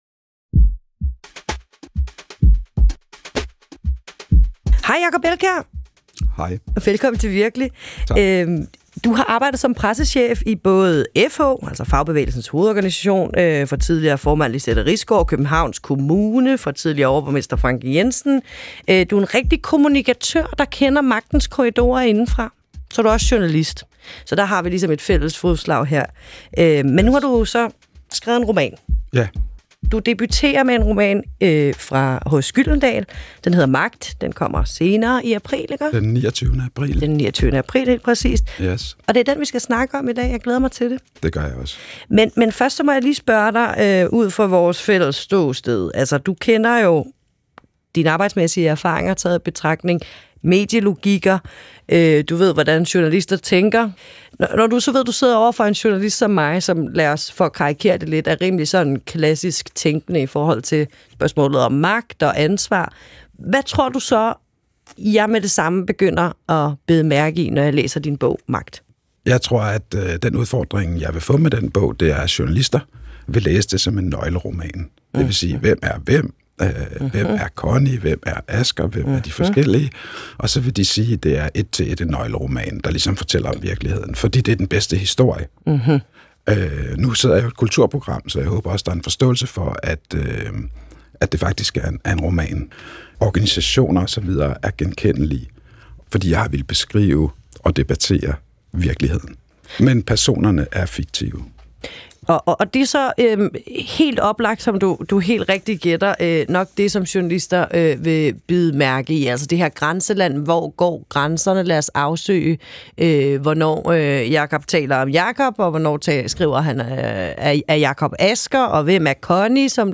En konkret samtale med Peter Sommer – Virkelig – Podcast